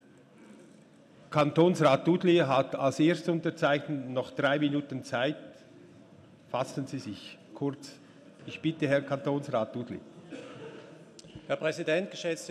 13.6.2019Wortmeldung
Ratspräsident:
Session des Kantonsrates vom 11. bis 13. Juni 2019